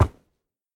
horse_wood5.ogg